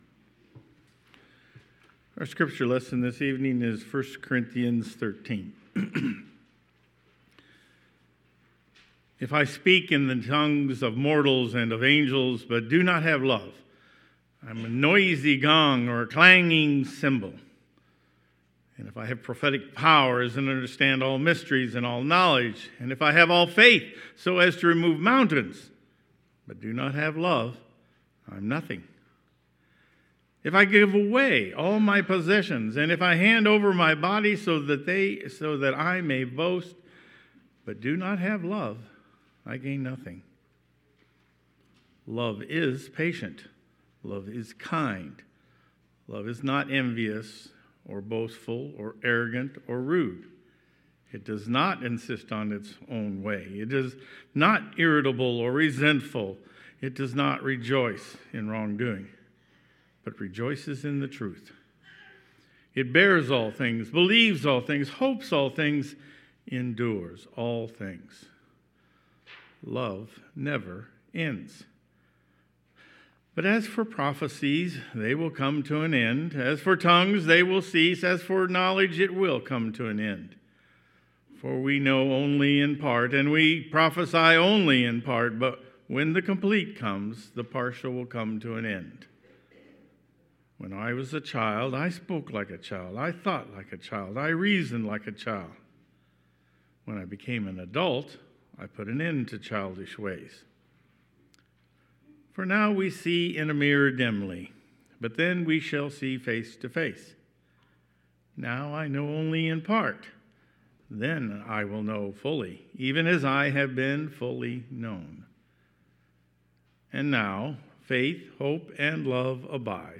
Community Lenten Service 2020 – First Presbyterian Church of Marion, Illinois
community-lenten-service-2020.mp3